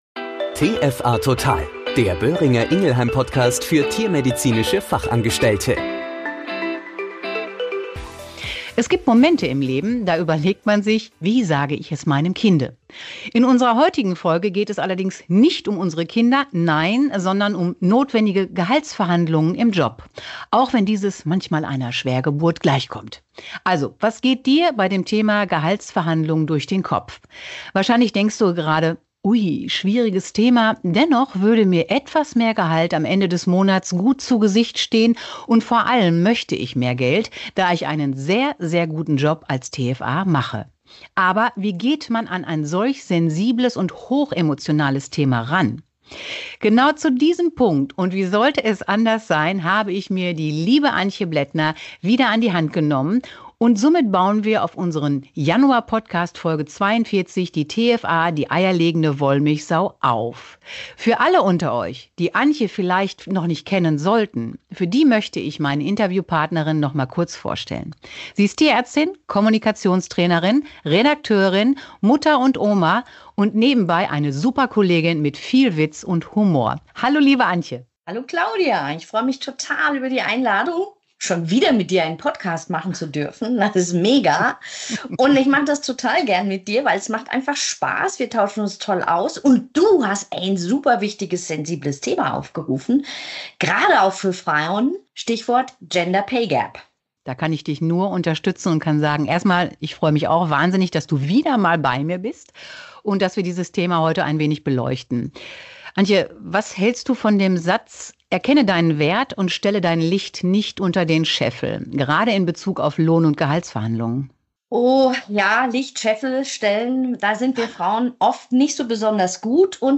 In diesem Interview mit einer renommierten Kommunikationstrainerin & Tierärztin, sollen wertvolle Tipps für die TFA zu diesem Thema vermittelt werden.